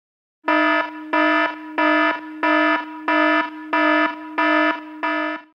alarm-sound-effect.mp3